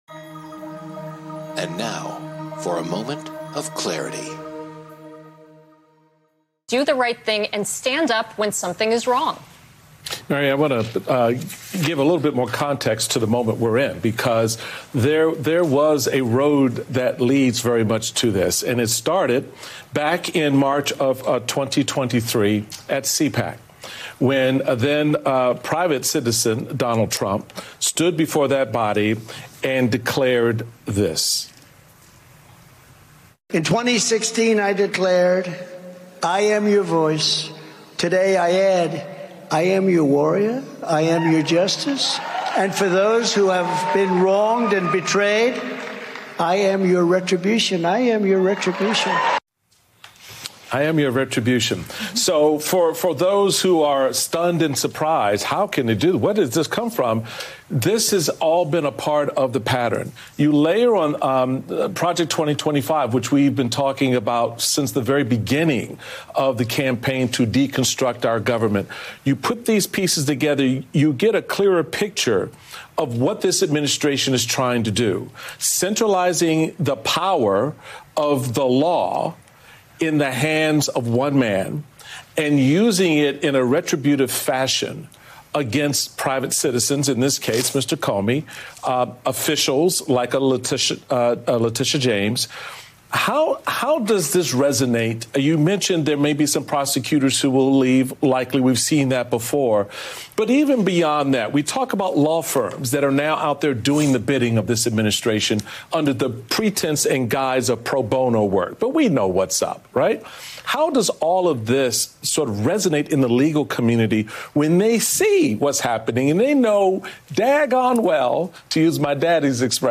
In this urgent analysis, he dissects the explosive indictment of former FBI Director James Comey and explains its real-world consequences. Is this the moment President Trump's administration fully erodes the rule of law?